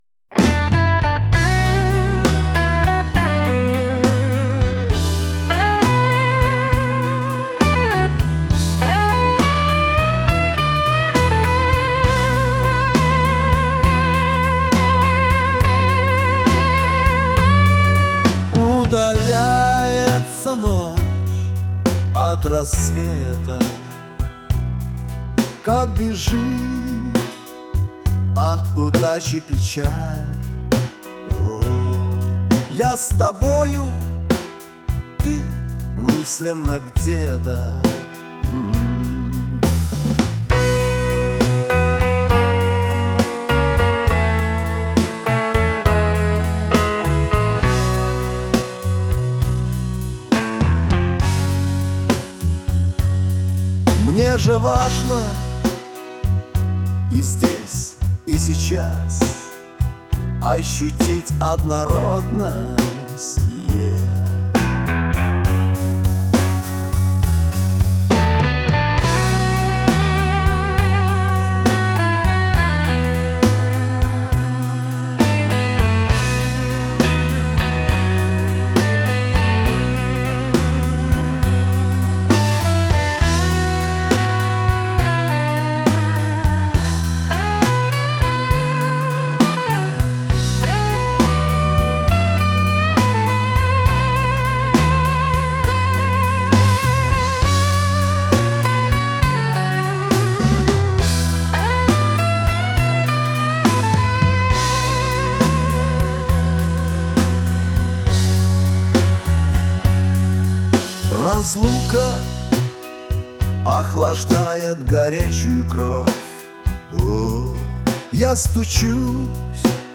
Блюз (1232)